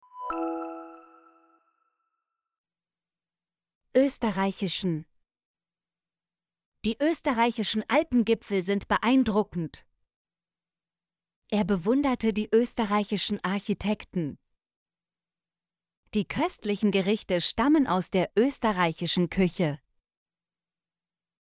österreichischen - Example Sentences & Pronunciation, German Frequency List